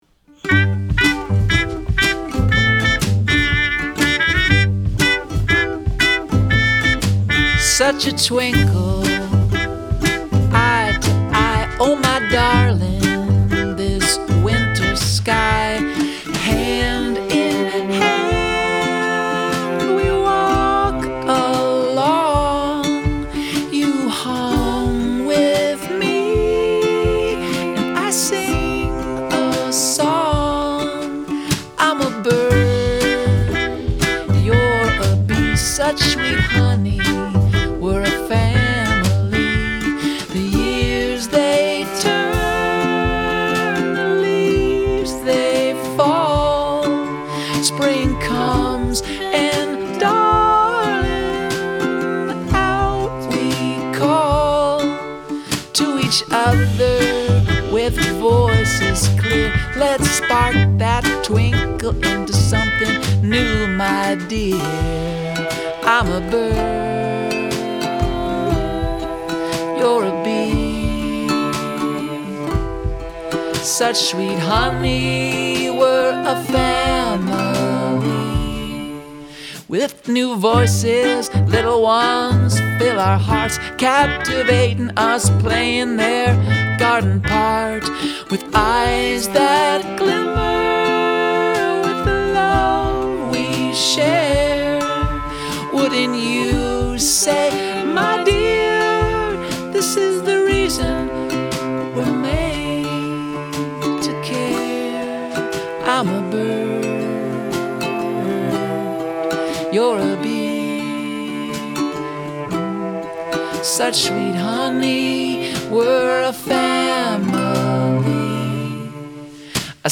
ukulele
trumpet
cello
Really nice harmonies here.